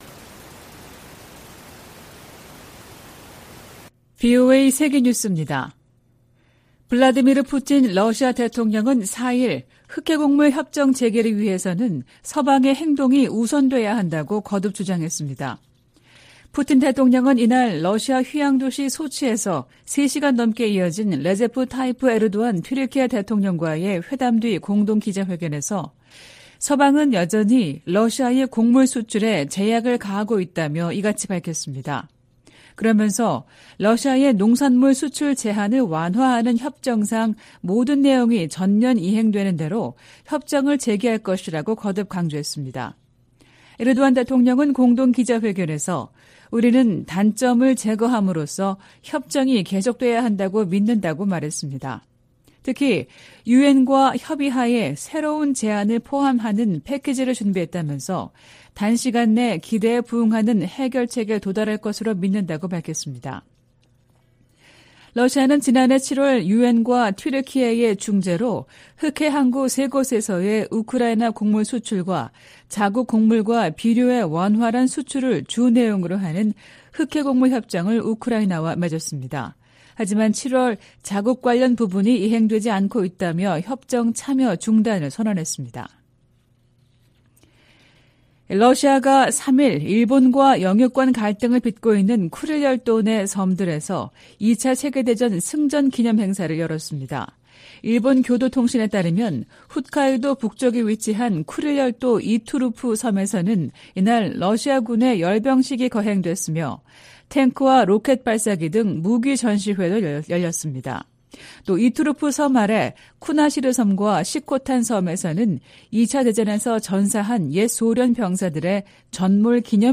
VOA 한국어 '출발 뉴스 쇼', 2023년 9월 5일 방송입니다. 북한이 전략순항미사일을 발사하며 핵 공격 능력을 과시하려는 도발을 이어갔습니다. 러시아가 북한에 북중러 연합훈련을 공식 제의했다고 한국 국가정보원이 밝혔습니다. 백악관은 바이든 행정부가 인도태평양 지역을 중시하고 있다고 거듭 강조했습니다.